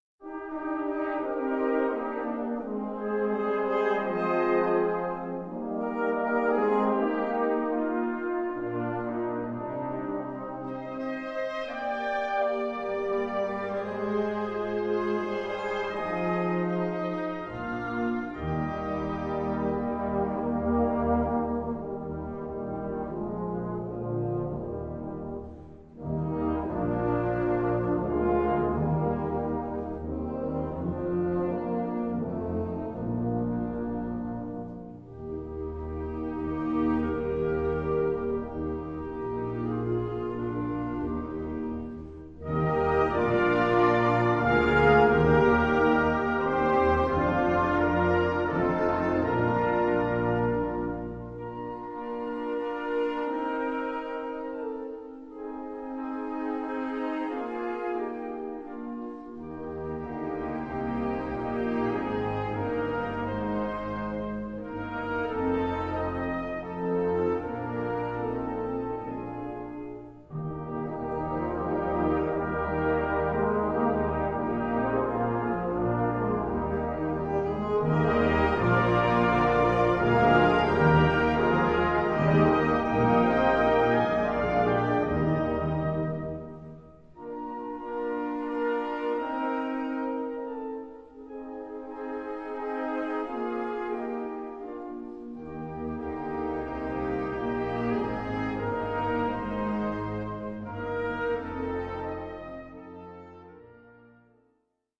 Gattung: Feierliche Musik
Besetzung: Blasorchester